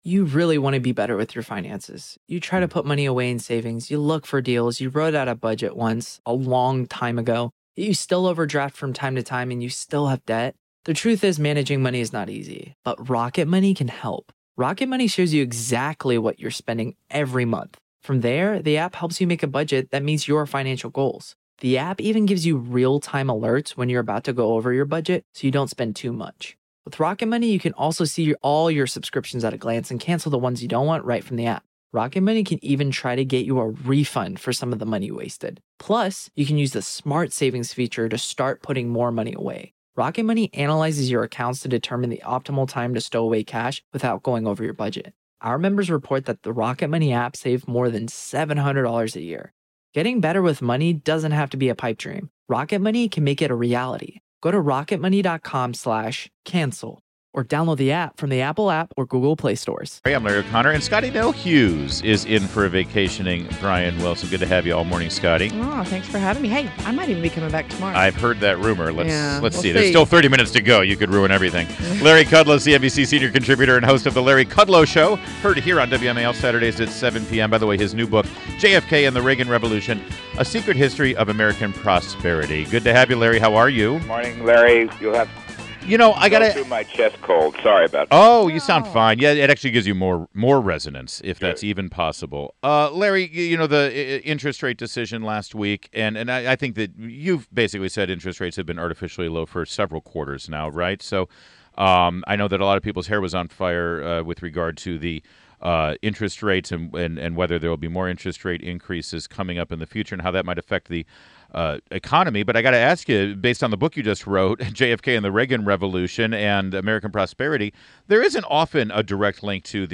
WMAL Interview - LARRY KUDLOW - 12.20.16